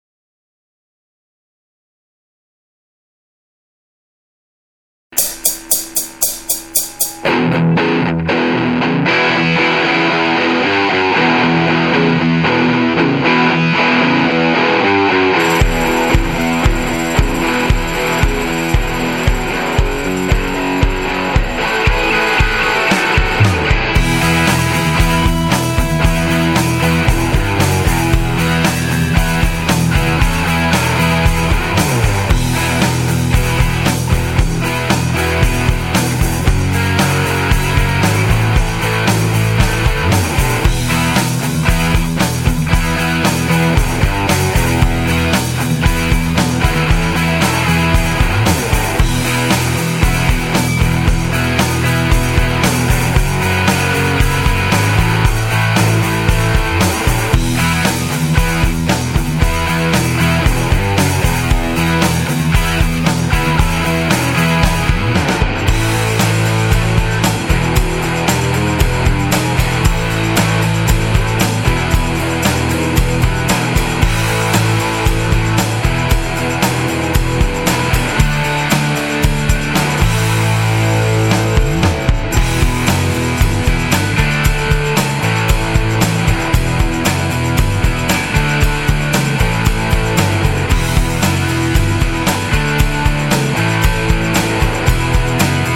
nasa studiovka
Preco brumi gitara na zaciatku?
inak musim podotknut ze to bolo hrane bez metraku :)